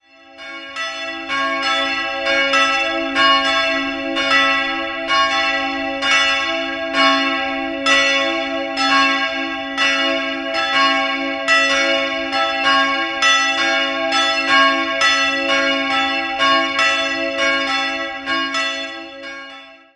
Sehenswert im schlichten Inneren ist besonders das bunte Glasfenster, das die Schutzmantelmadonna zeigt. 2-stimmiges Kleine-Terz-Geläute: cis''-e'' Die Glocken wurden in den Jahren 1966 und 1967 von Friedrich Wilhelm Schilling in Heidelberg gegossen.